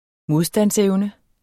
Udtale [ ˈmoðsdans- ]